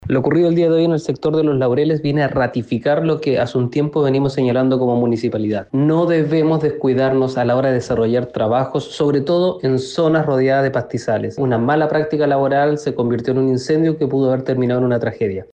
Debido a esta emergencia, el alcalde de la comuna de Limache, Luciano Valenzuela, señaló que este incendio viene a ratificar que las personas no se pueden descuidar al momento de realizar trabajos.